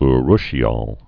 (-rshē-ôl, -ōl, -ŏl)